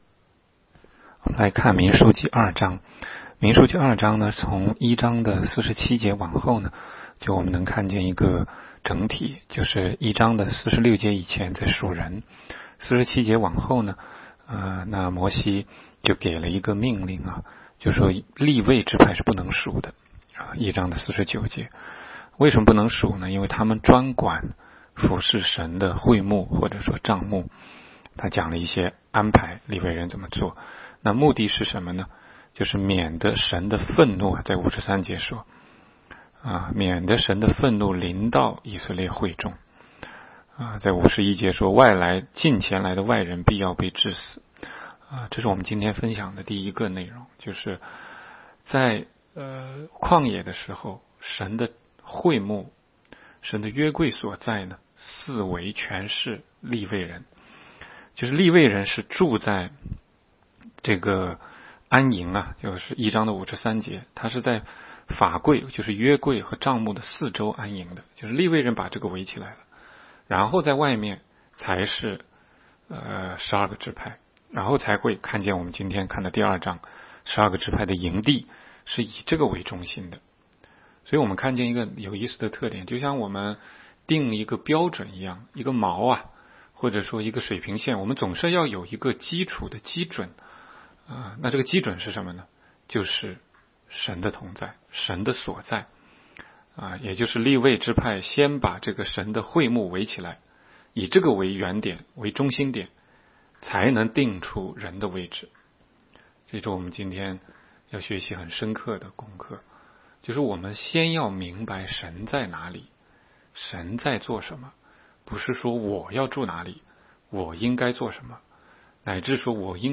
16街讲道录音 - 每日读经-《民数记》2章